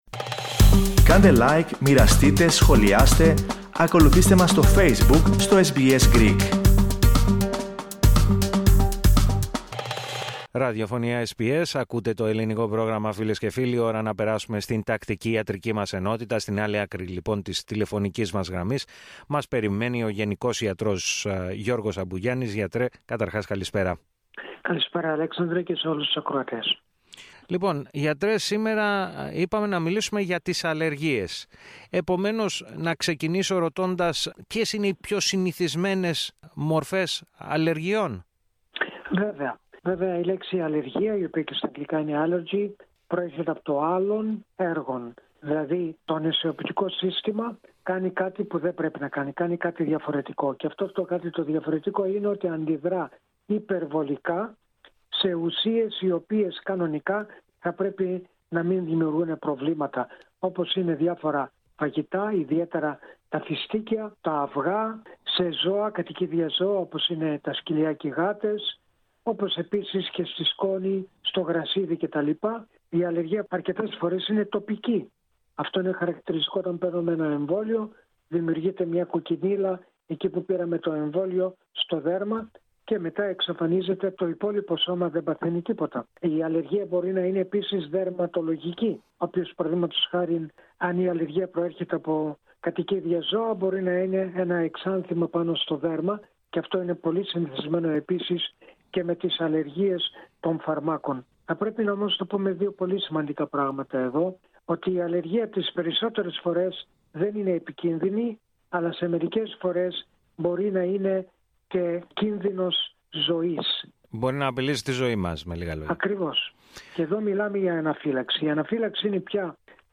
Ακούστε, όμως, ολόκληρη τη συνέντευξη, πατώντας το σύμβολο στο μέσο της κεντρικής φωτογραφίας.